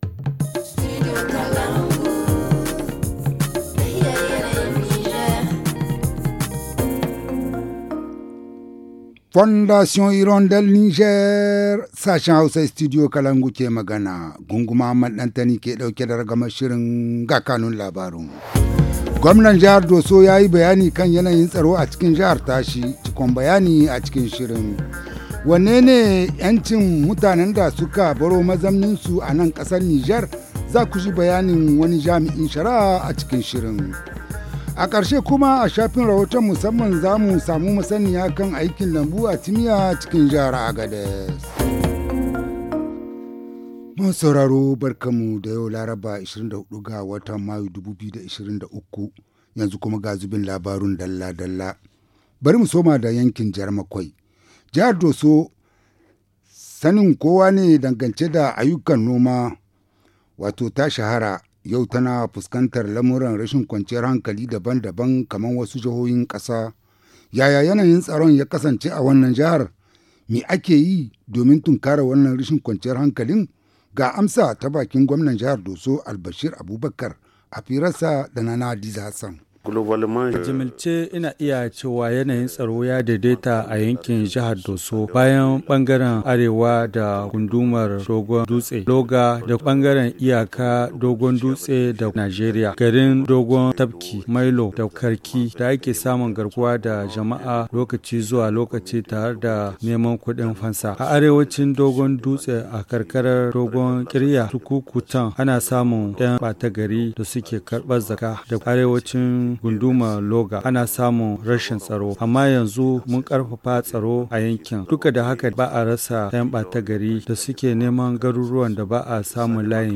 Le journal du 24 mai 2023 - Studio Kalangou - Au rythme du Niger